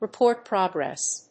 アクセントrepórt prógress